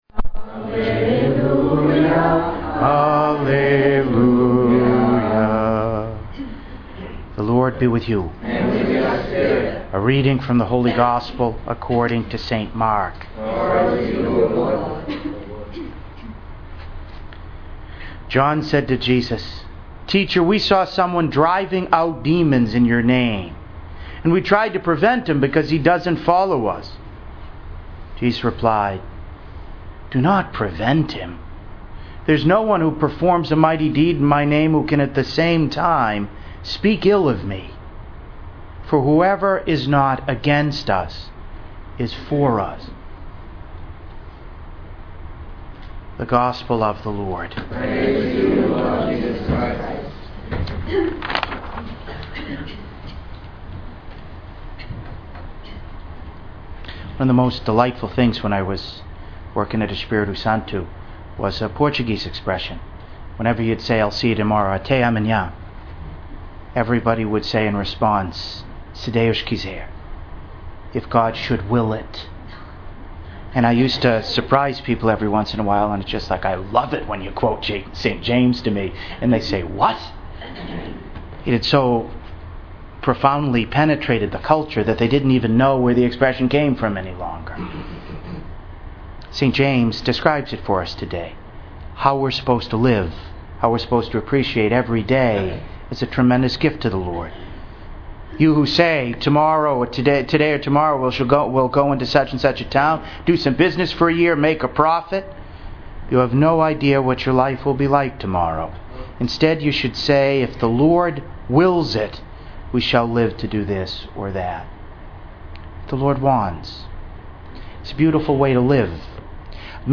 St. Bernadette Parish, Fall River, MA
Wednesday of the Seventh Week in Ordinary Time, Year II